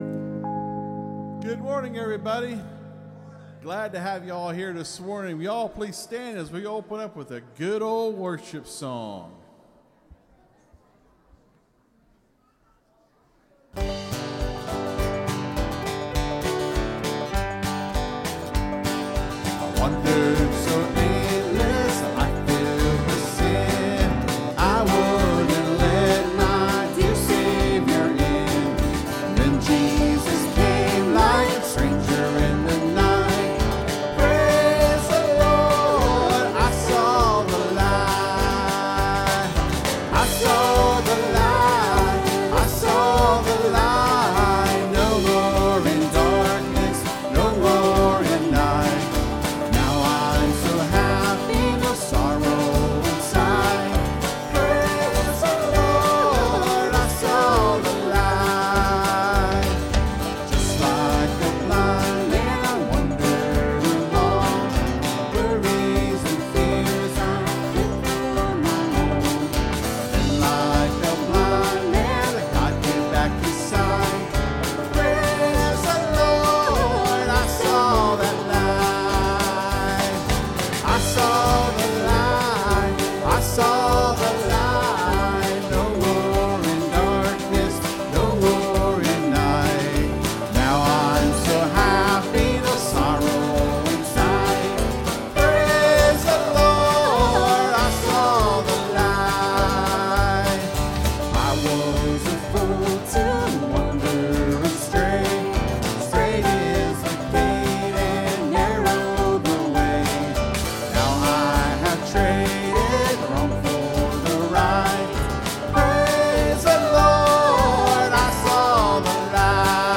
(Sermon starts at 27:50 in the recording).